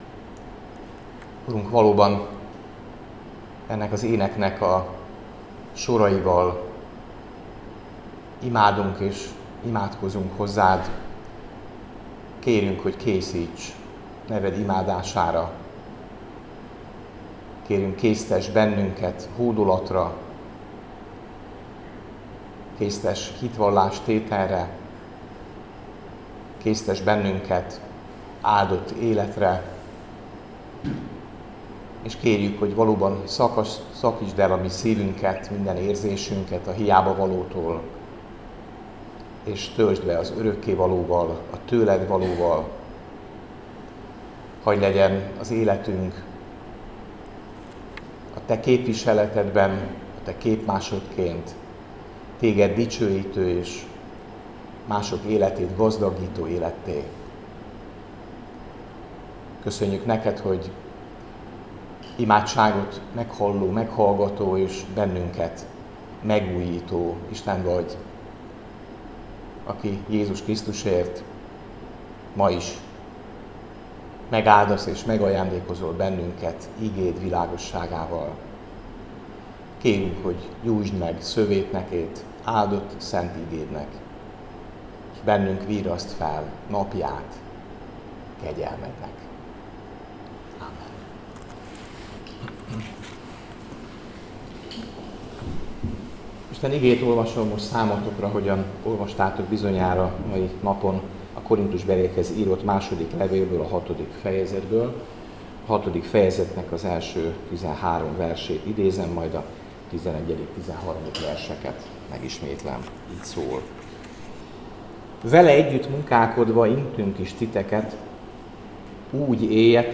Áhítat, 2025. május 6.